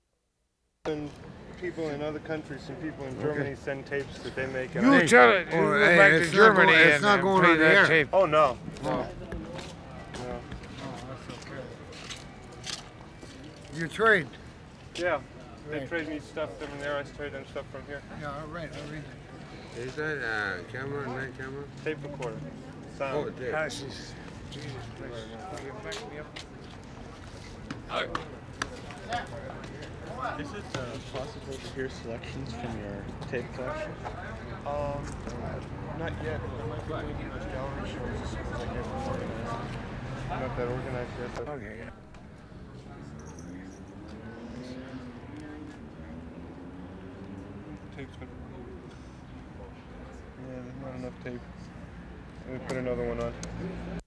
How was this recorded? WORLD SOUNDSCAPE PROJECT TAPE LIBRARY GASTOWN (V) June 3, 1973